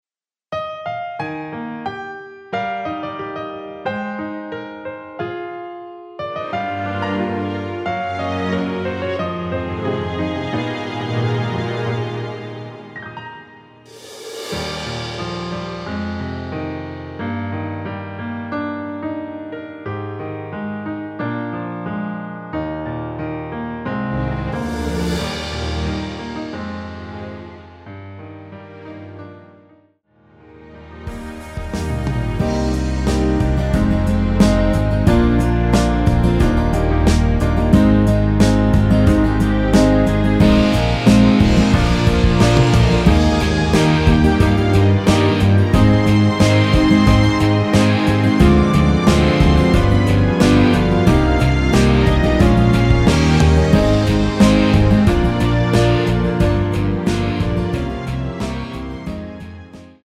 *(-2) 내린 MR 입니다.
여성분이 부르실 축가로 좋은곡
Eb
앞부분30초, 뒷부분30초씩 편집해서 올려 드리고 있습니다.